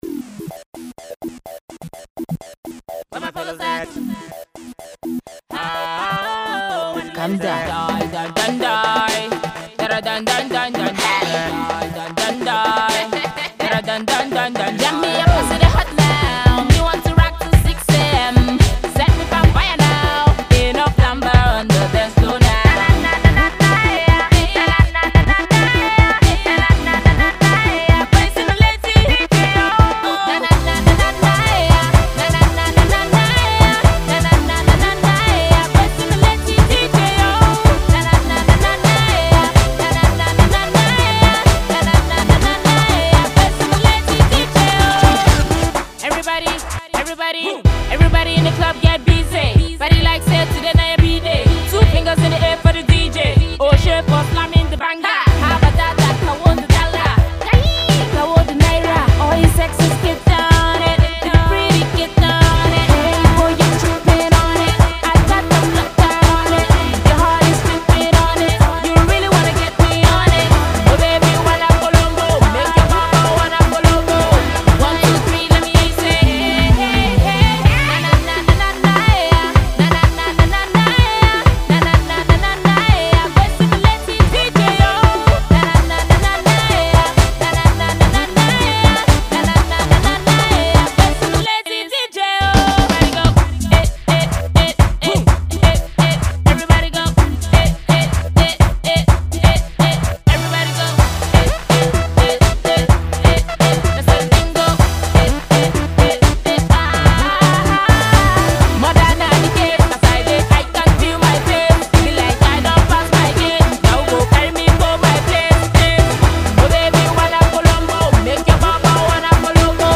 she takes a different route towards the Afro Pop genre.